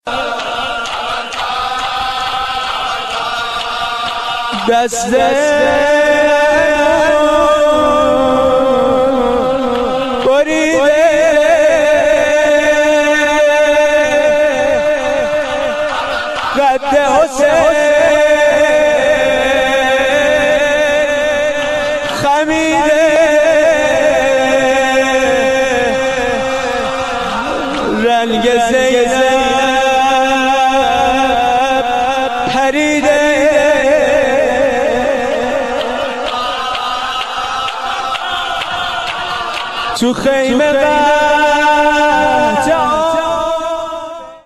شور
مداحی در محرم